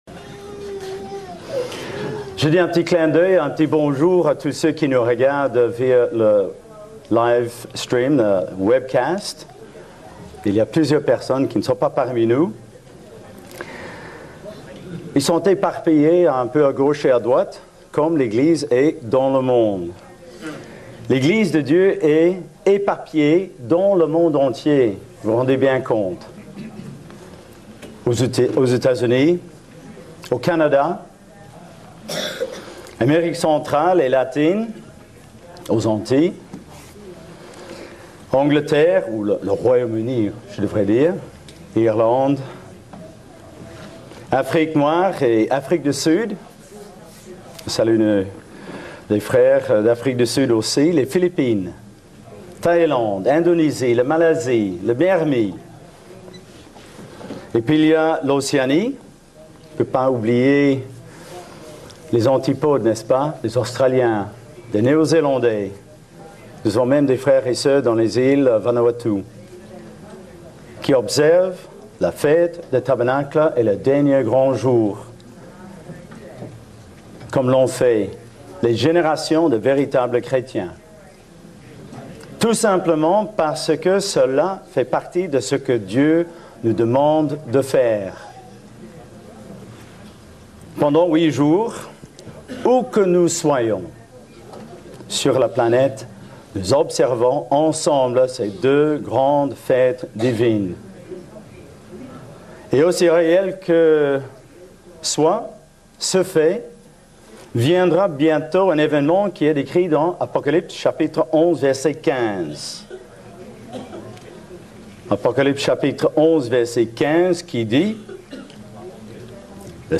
Fête des Tabernacles – 2ème jour